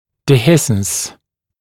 [dɪ’hɪsns][ди’хиснс]частичное обнажение (пришеечного участка корня зуба), расхождение (краев раны), дегисценция (щель в костном канале или стенке костной полости)